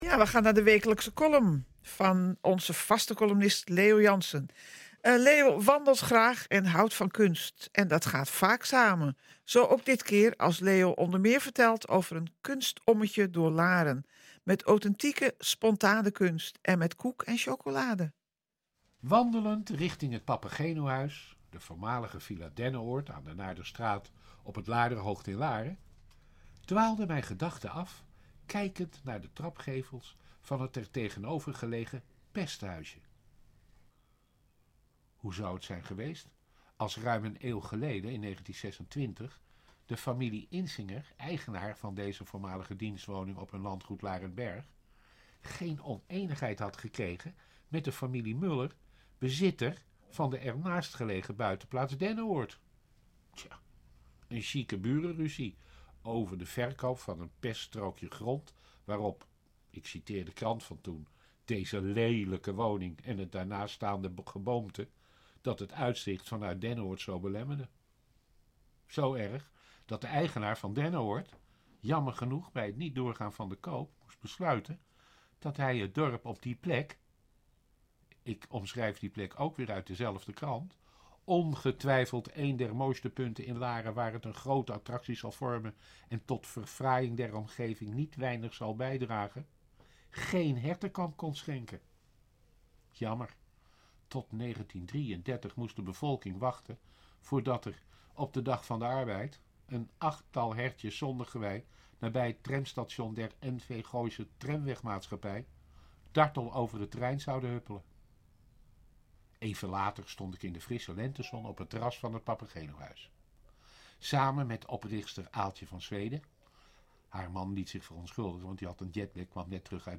NH Gooi Zaterdag - Column